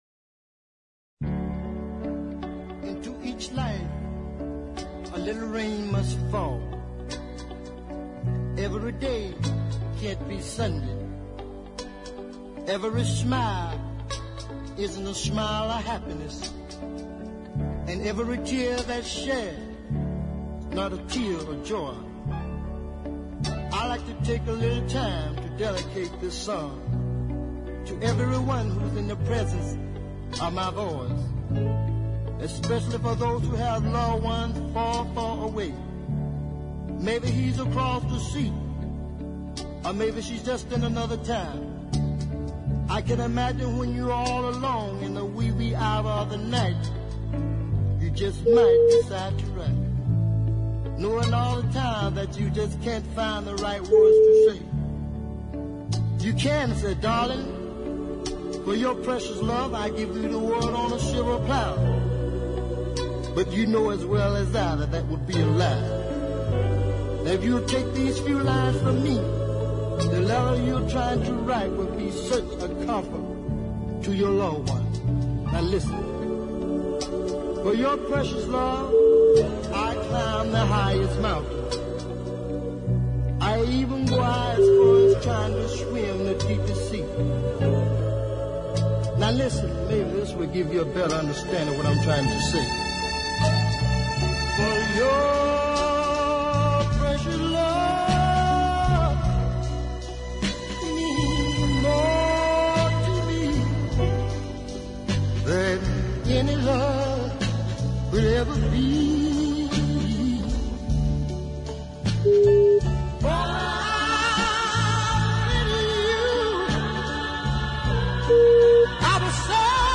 pre-soul gospel-meets-doo-wop song
sermon-like rap intro
female back-up vocals
and then strings